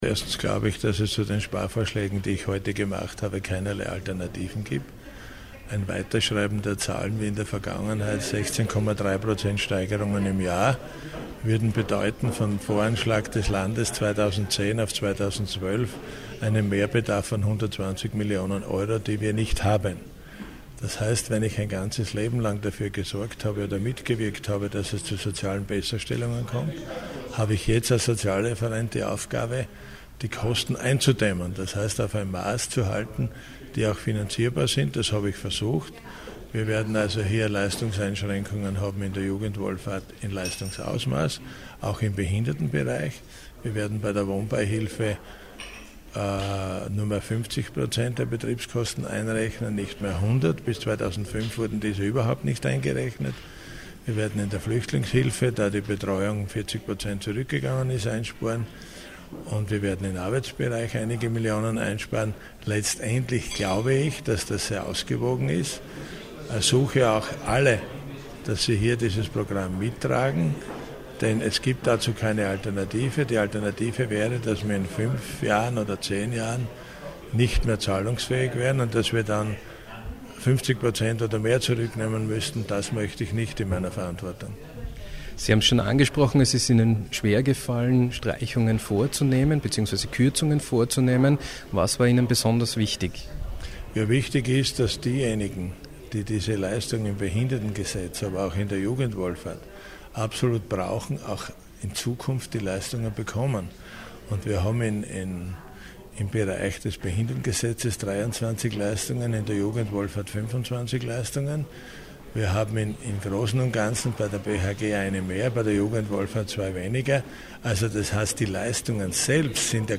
O-Töne LH-Stv. Siegfried Schrittwieser: